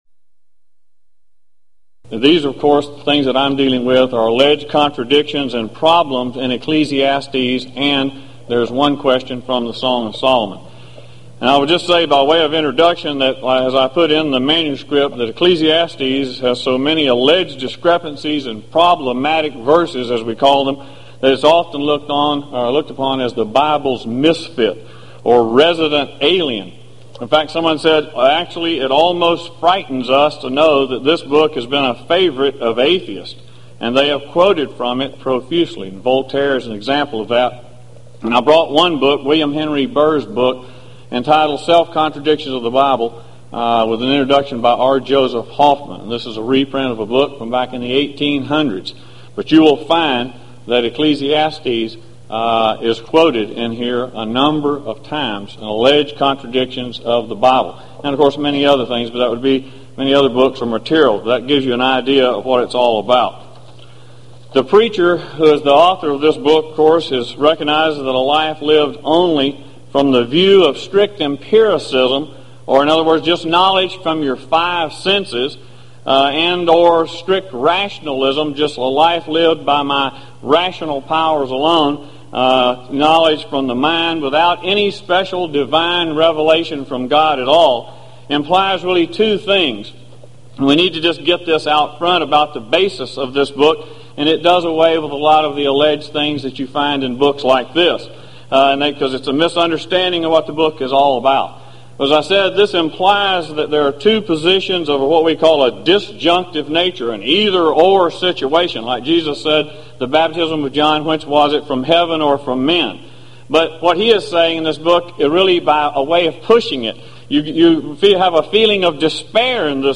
Event: 1995 Gulf Coast Lectures
lecture